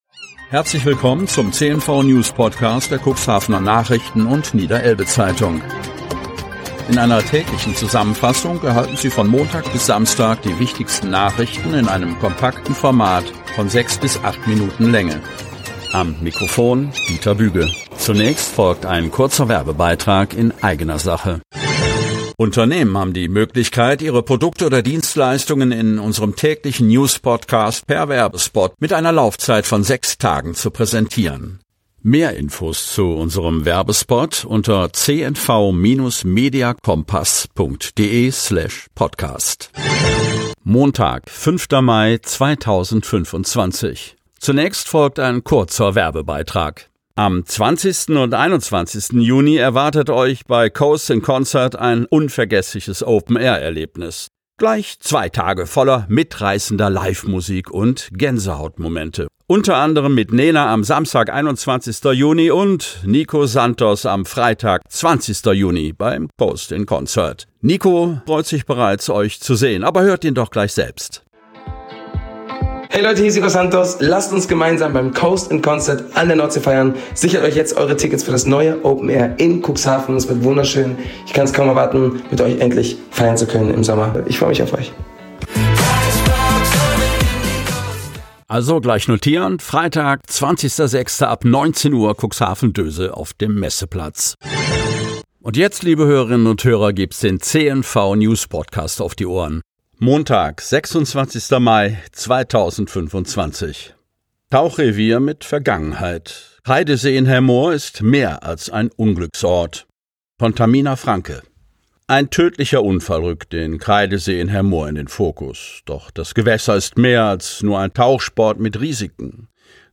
Genres: Daily News , News , Sports